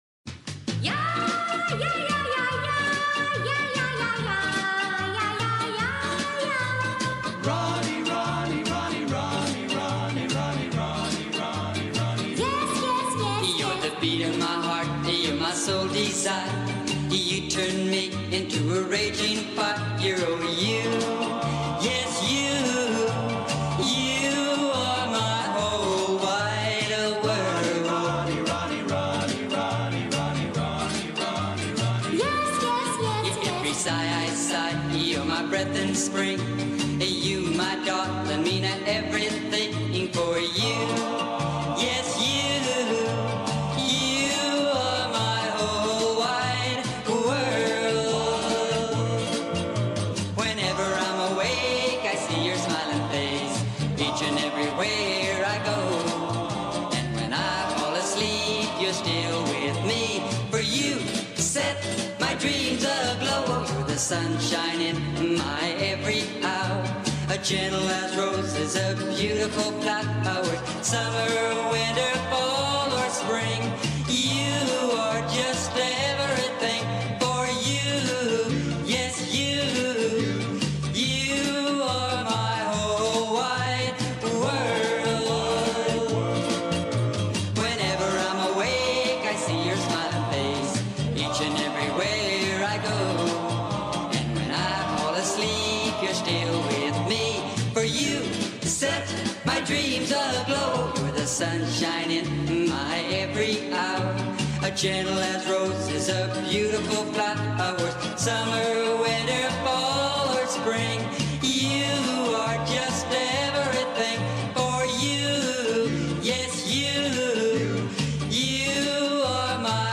batería
guitarras
bajo
Teclados
trompeta
Trombón
acordeón